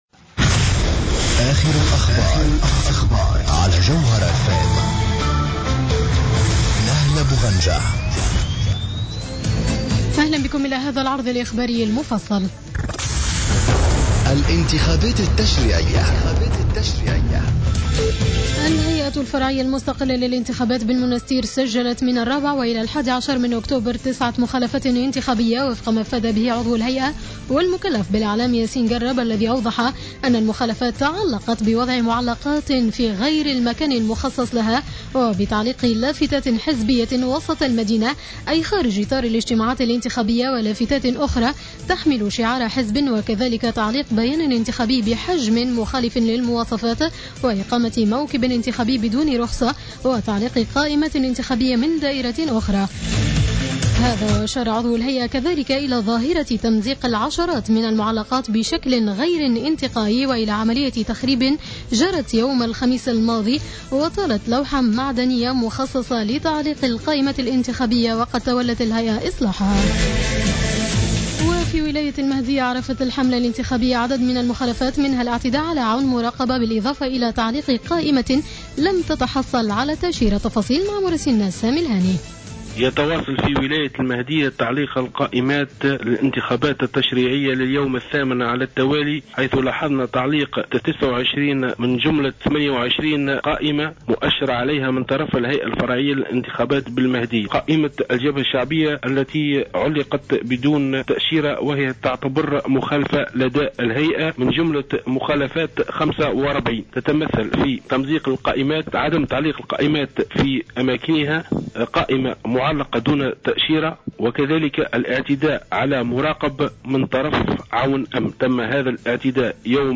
نشرة أخبار منتصف الليل ليوم الأحد 12-10-14